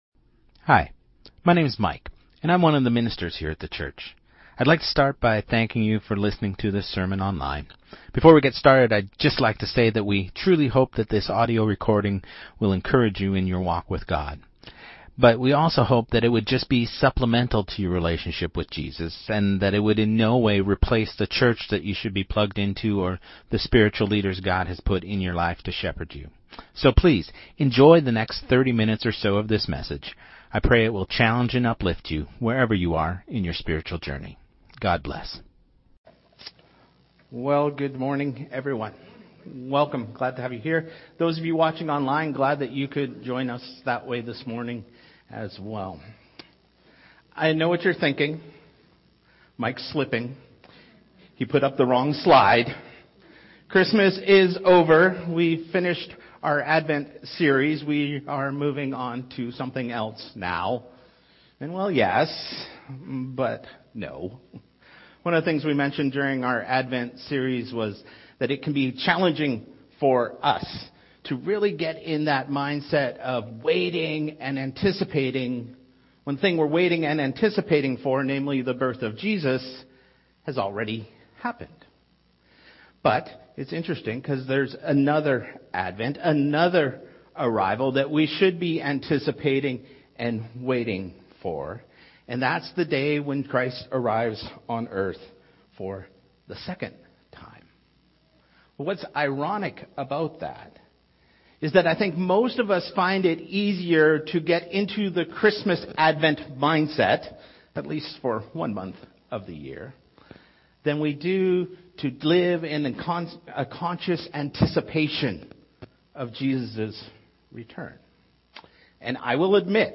Sermon2026-01-11